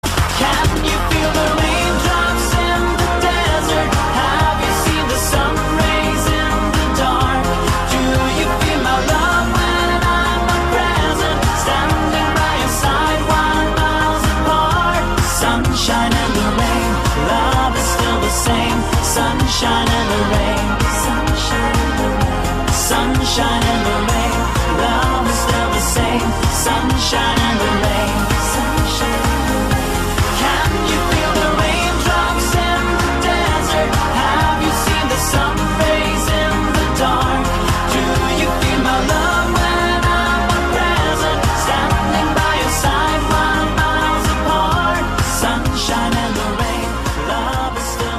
giai điệu disco-pop đầy hứng khởi